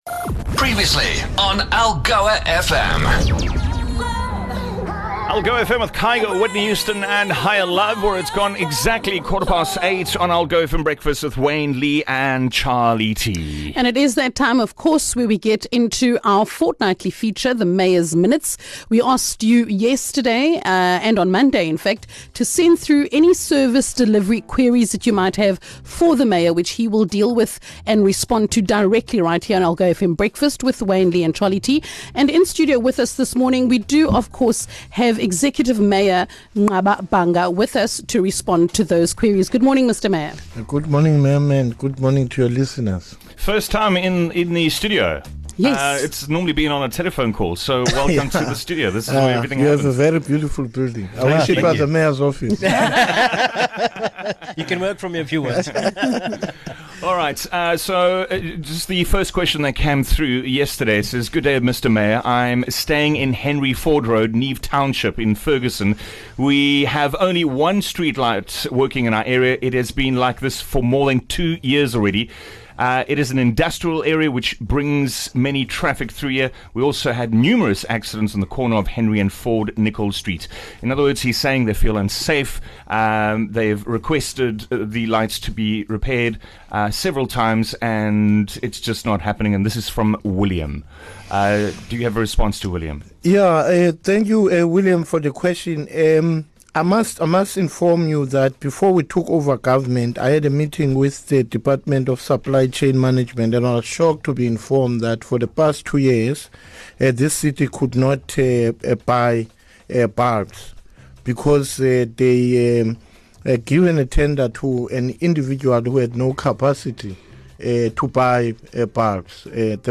Executive Mayor Nqaba Bhanga joined the breakfast team in-studio for the first time since the pandemic hit to answer your service delivery questions - the message was clear - righting the wrongs of the previous administration is going to take time.